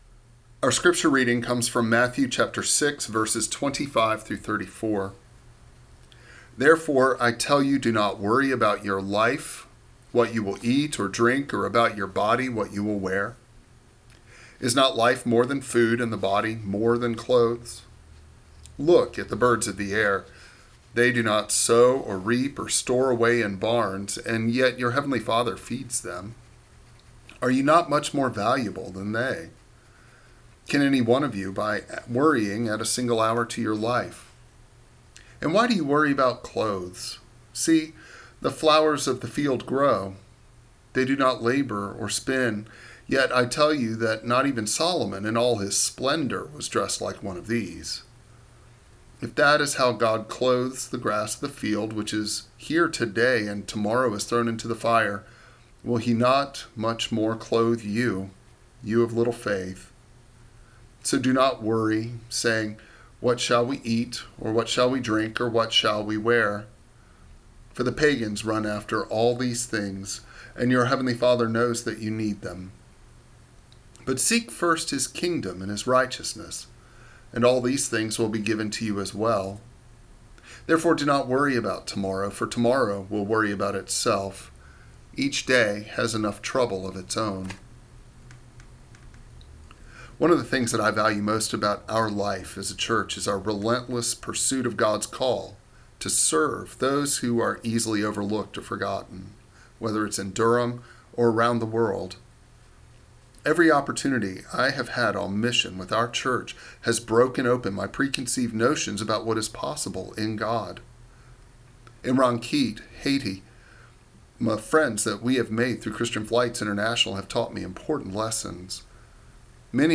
Meditation
March15-Meditation.mp3